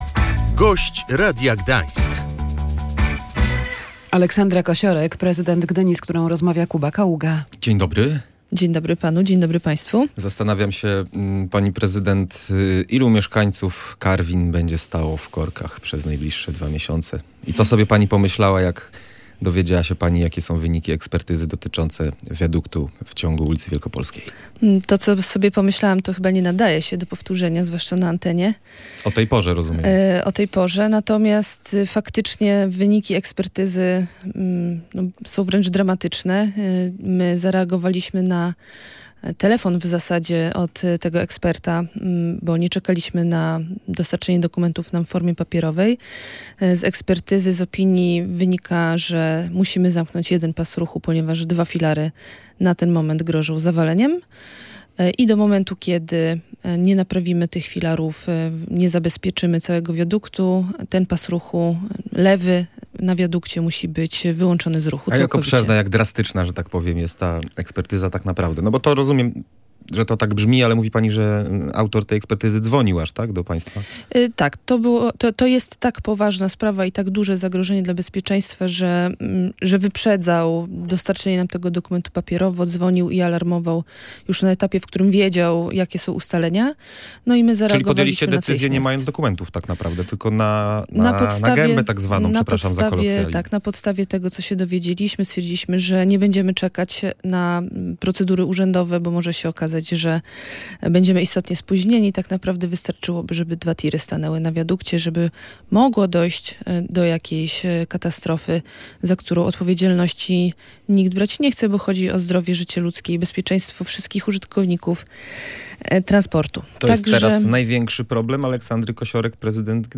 Ekspertyza dotycząca fatalnego stanu wiaduktu na węźle Karwiny była ostatnim argumentem, by złożyć zawiadomienie do prokuratury w sprawie inwestycji – mówiła w Radiu Gdańsk prezydent Gdyni Aleksandra Kosiorek.